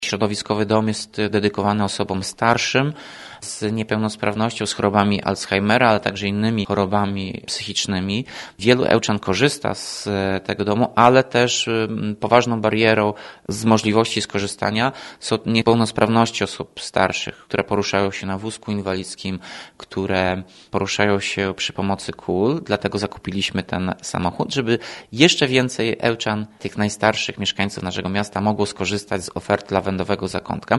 Mówi Tomasz Andrukiewicz, prezydent Ełku: